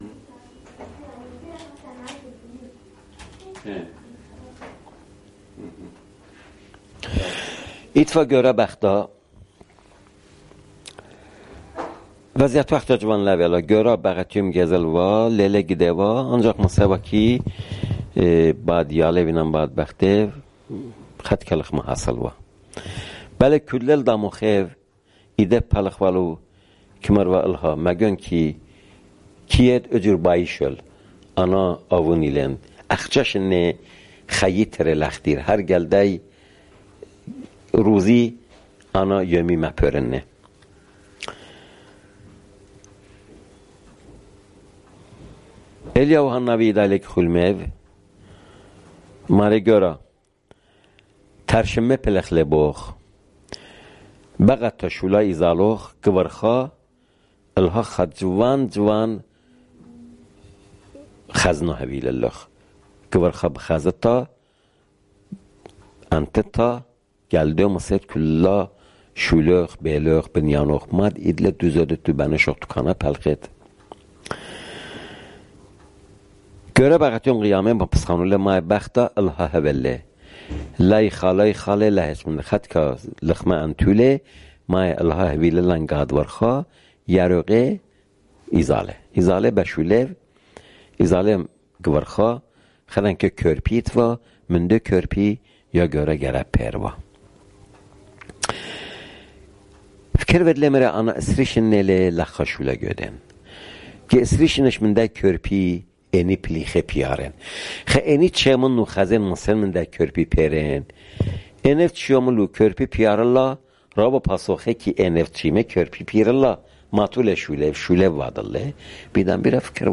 Urmi, Jewish: A Missed Opportunity